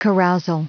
Prononciation du mot carousal en anglais (fichier audio)
Prononciation du mot : carousal